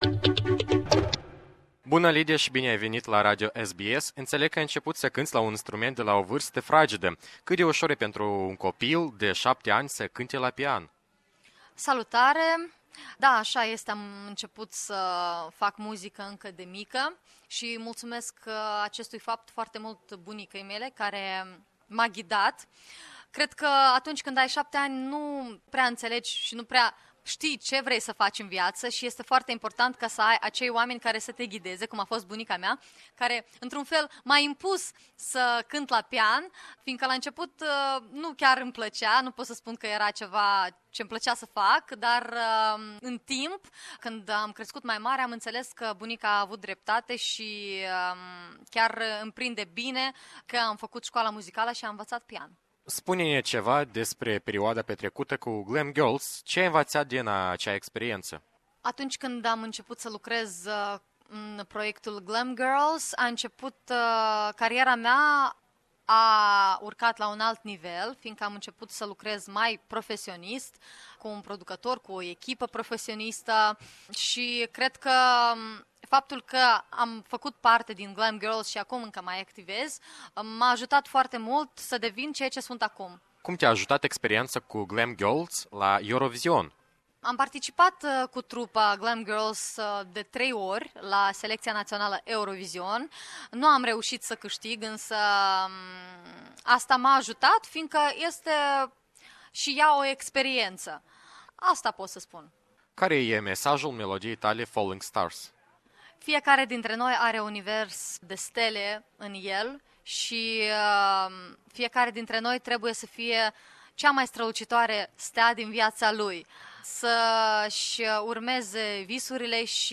Interviu cu Lidia Isac, reprezentanta Republicii Moldova la Eurovizion 2016